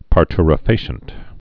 (pär-trə-fāshənt, -tyr-)